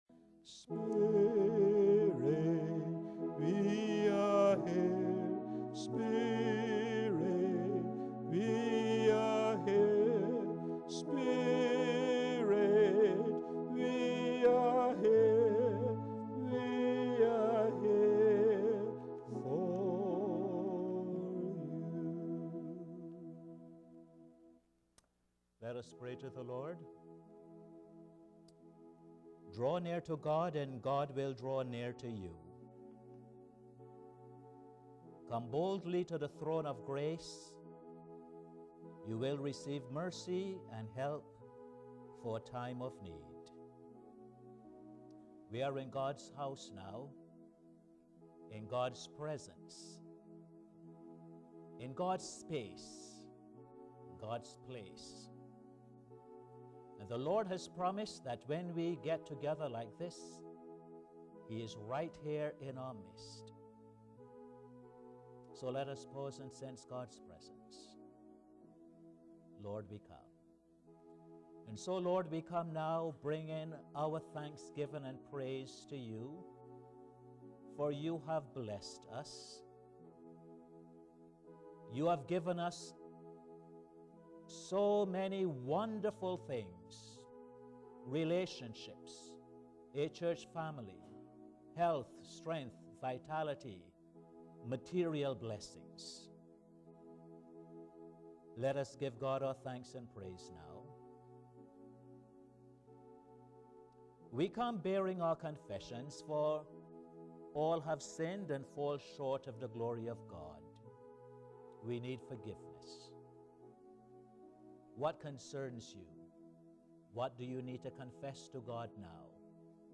Posted in Sermons on 20.